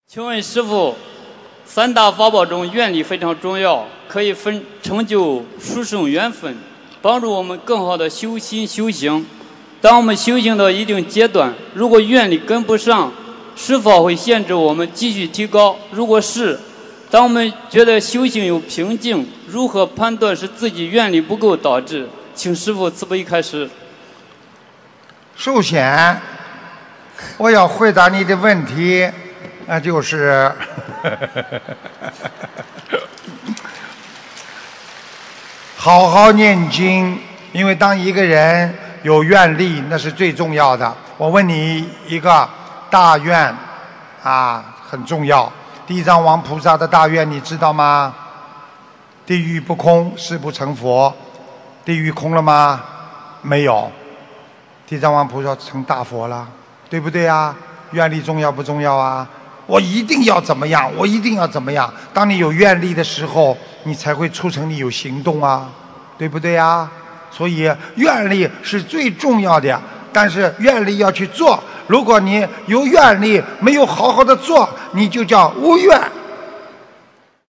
愿力和修行的关系；发了愿就要好好地做┃弟子提问 师父回答 - 2017 - 心如菩提 - Powered by Discuz!